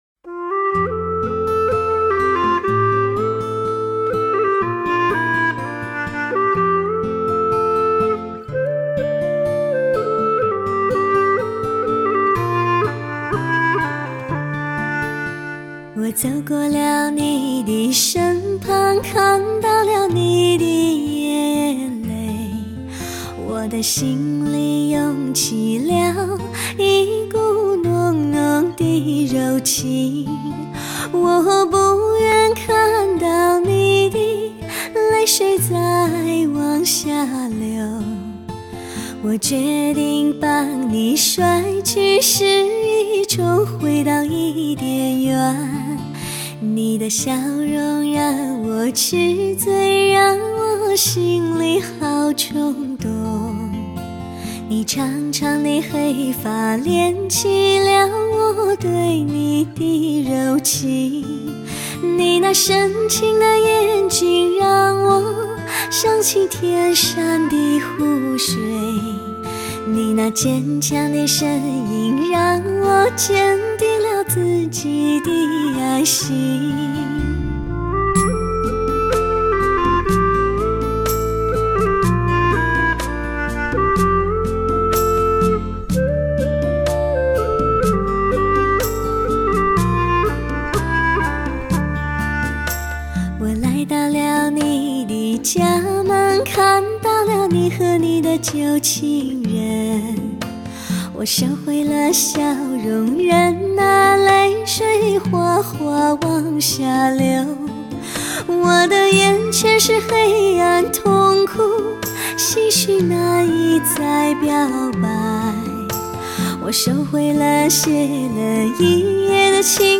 纯原女声呈现能够触摸到的真实感，闻得到的清香，看得见的新鲜。